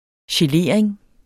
Udtale [ ɕeˈleˀɐ̯eŋ ]